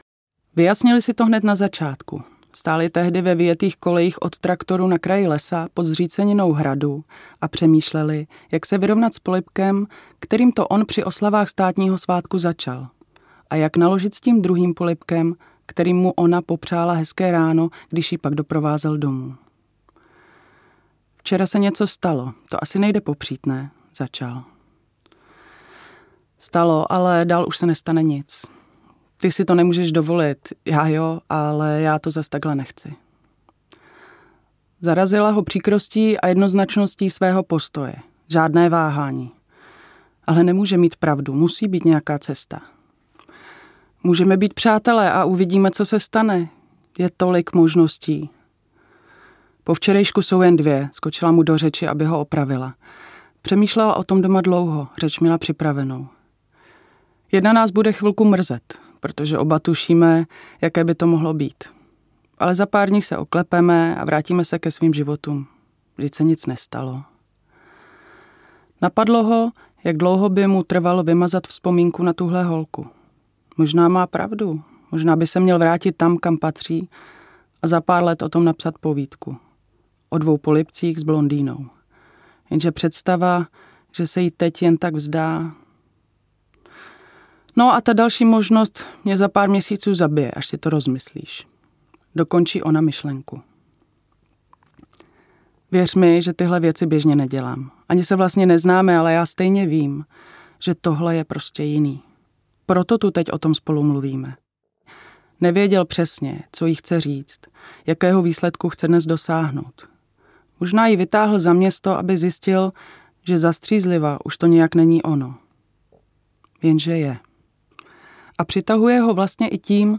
Setkání audiokniha
Ukázka z knihy
| Vyrobilo studio Soundguru.